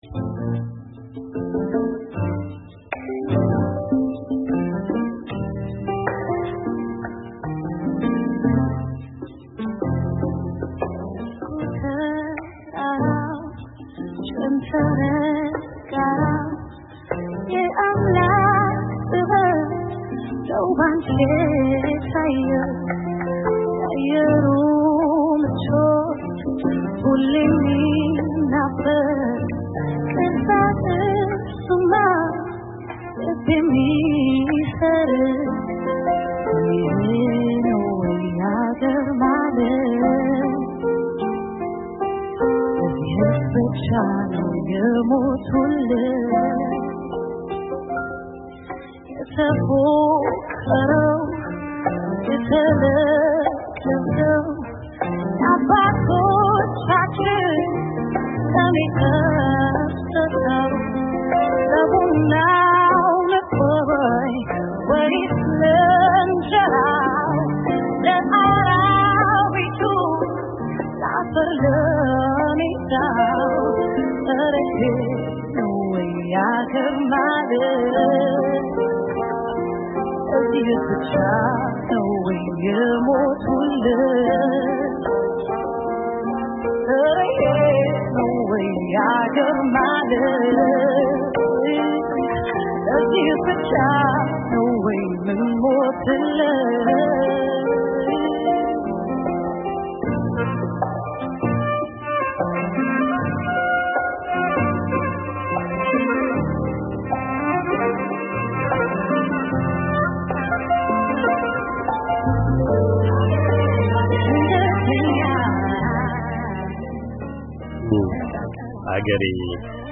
“በሕይወት አንዴ” የሚባል ዓይነት ገጠመኝ ነው፤ እንደ ዋናው ታሪክ ሁሉ ታሪካዊ መሆን የቻለ። የሳምንቱ ታላቅ ዜና ሆኖ በሰነበተው የኖቤል የሰላም ሽልማት መድረክ የተሰሙ ዜማዎች ናቸው። በዚያ ልዩ መድረክ በተጫወተችውና በቀጥታ በቴሌቭዥን በተሰራጩት ሁለት ዘፈኖቿ -‘አገሬ’ እና ‘እወድሃለሁ’ የኢትዮጵያን ባሕል ለዓለም የማስተዋወቅ ልዩ ዕድል የገጠማት ወጣቷ ድምጻዊት ብሩክታዊት ጌታሁን - “ቤቲ ጂ” በስልኩ መስመር ወደ አሜሪካ ድምጽ ብቅ ብላለች። ስለ...